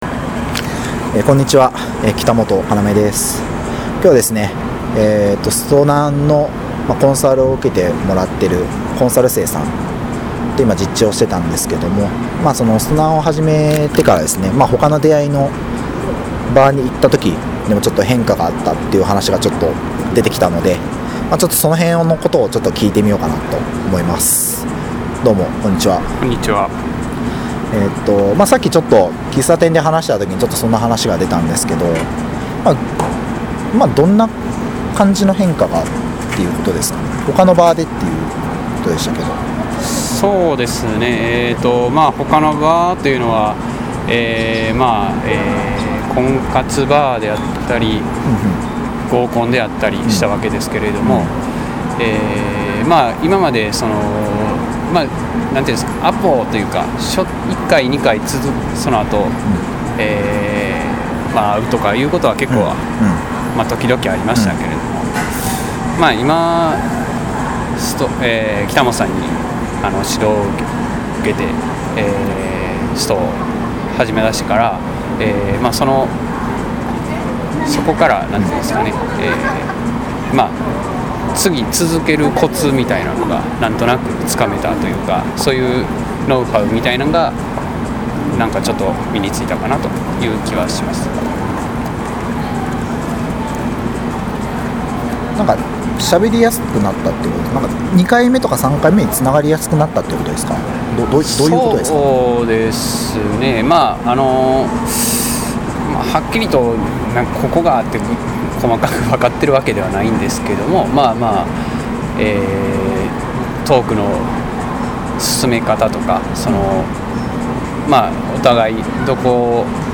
せっかくだったのでインタビューをさせて頂きました。
実践会参加者さん対談音声はコチラ